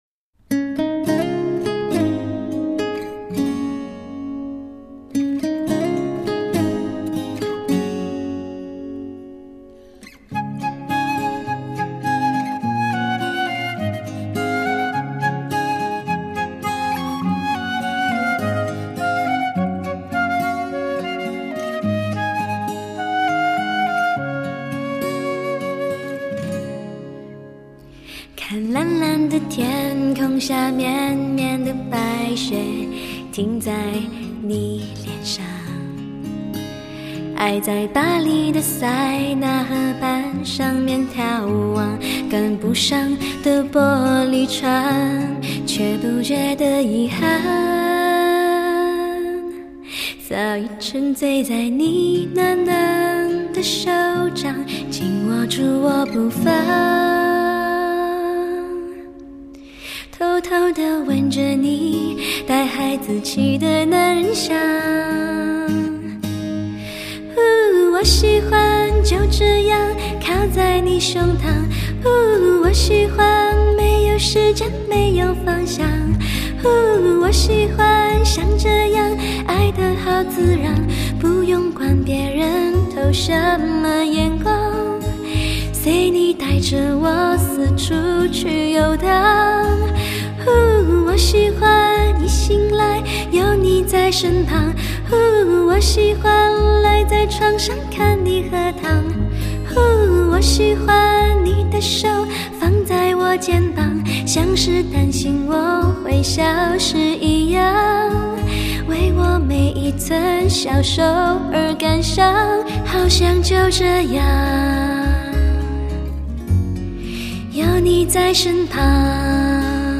华语流行
声音通透，高度传真，歌声甜美可人，唱韵细腻自然，别具迷人纯美吸引力。